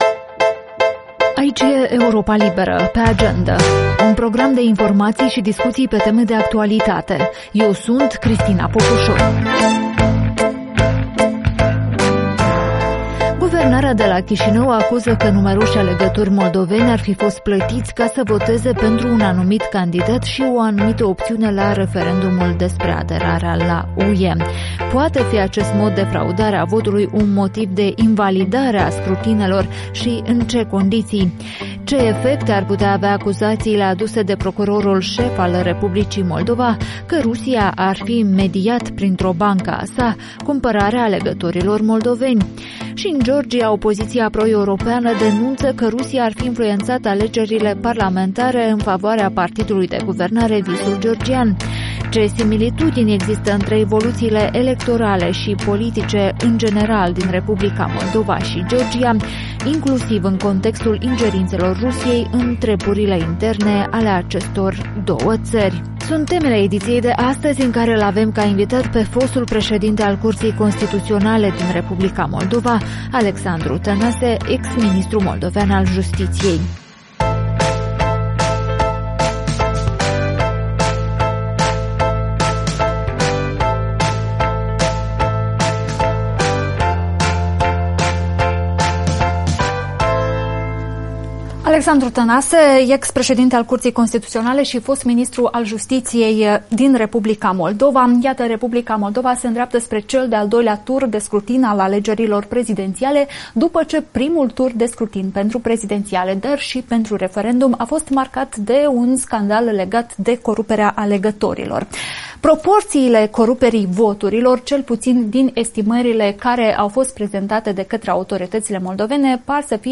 Despre motivele ce pot știrbi din legitimitatea acestor scrutine, încărcătura lor geopolitică, dar și lecțiile care pot fi învățate din ultimele evoluții discutăm cu constituționalistul Alexandru Tănase, fost președinte al Curții Constituționale, fost ministru al Justiției